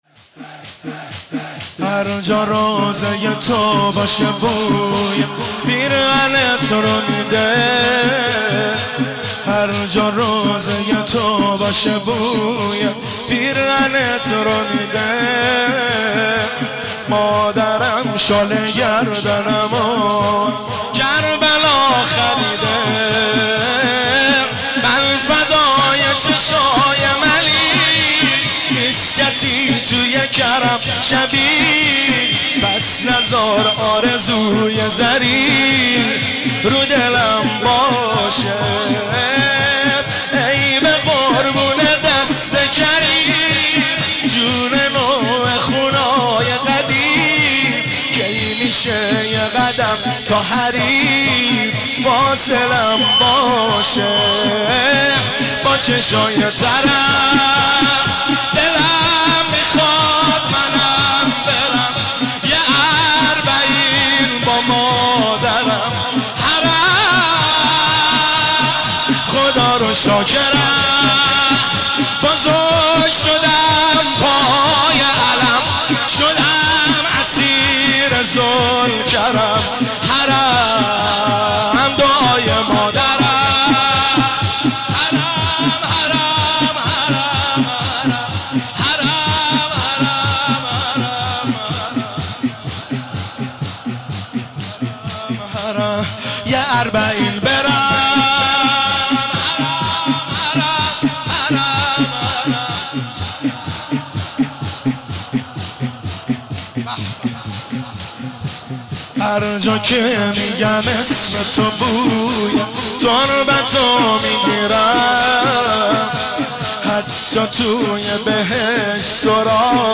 خیمه گاه - هیئت بین الحرمین طهران - شور – هر جا روضه تو باشه بوی پیرهنت رو میده